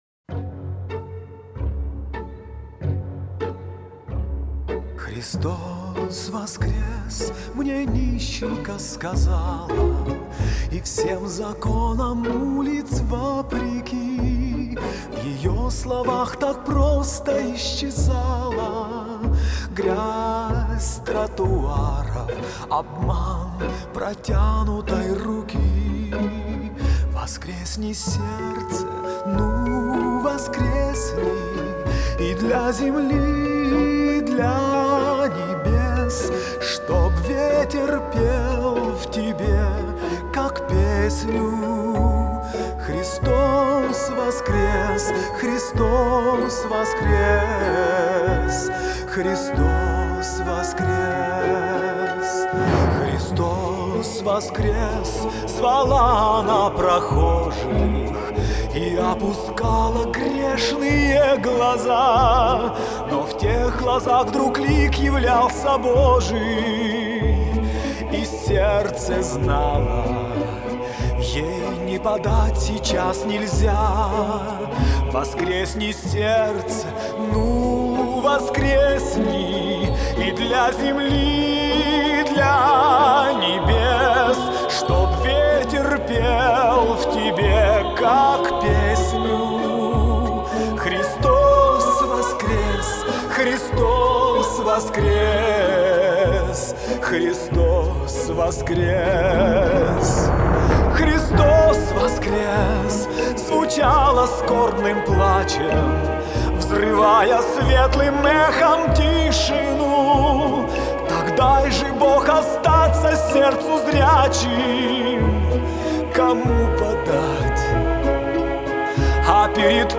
Красивое поздравления с Пасхой в песне